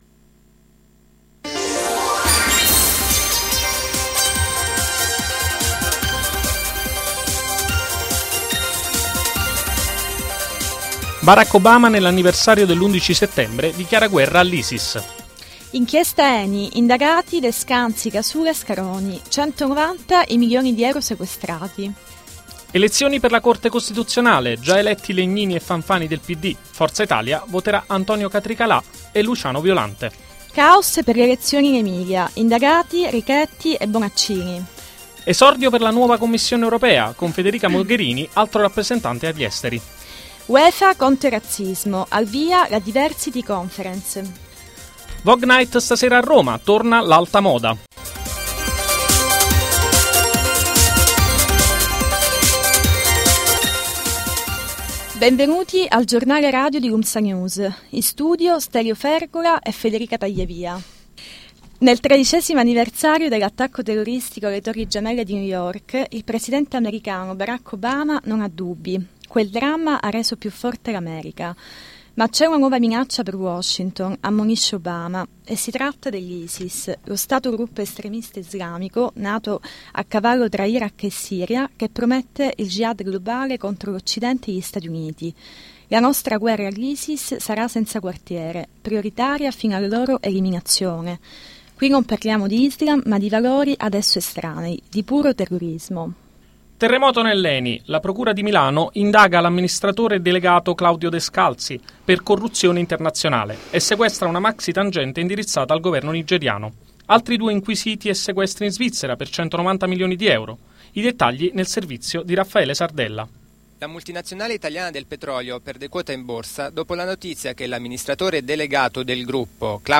In studio: